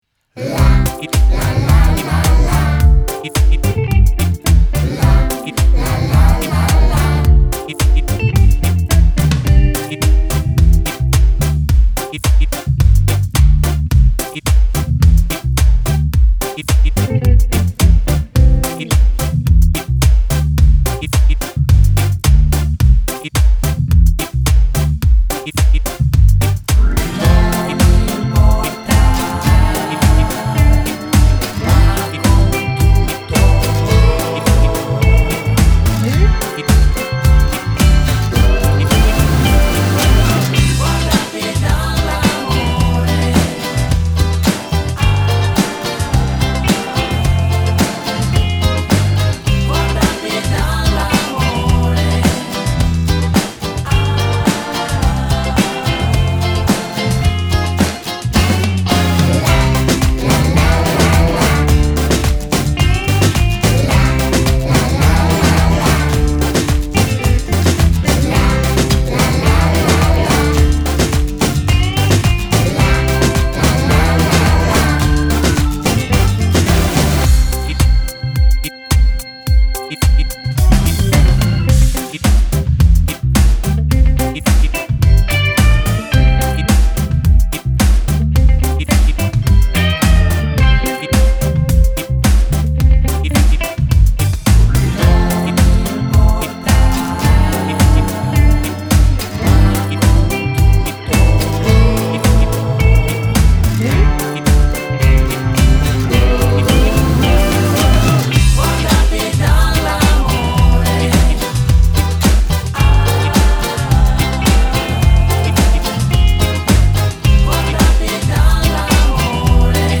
File Mp3 Testo con accordi Base musicale